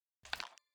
sprayer_take_oneshot_003.wav